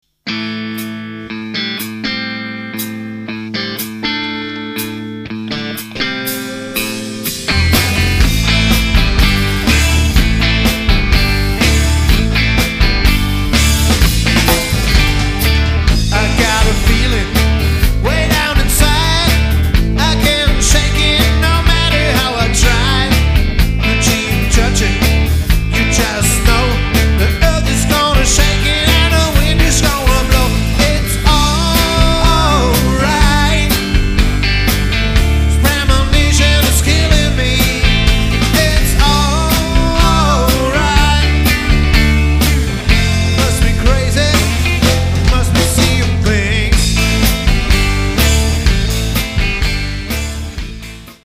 Epoche: 60er, 70er, 80er und mehr
Stilistik: Oldies, Rock'n'Roll, Classic-Rock